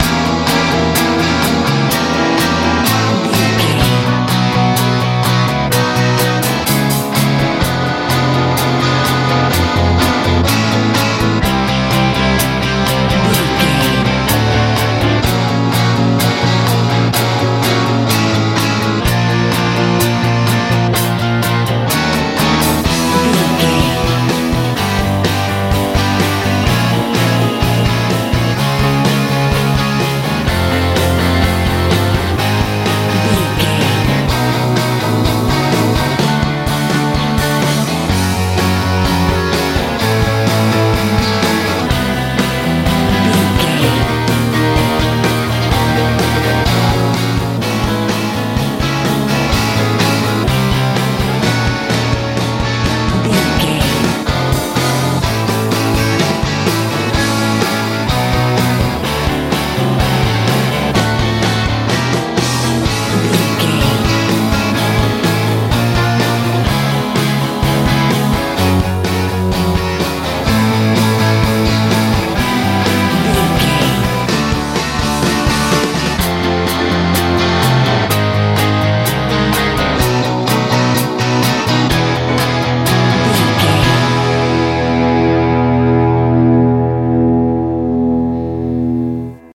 90s rock
Ionian/Major
B♭
bright
bass guitar
drums
electric guitar
piano
suspense
strange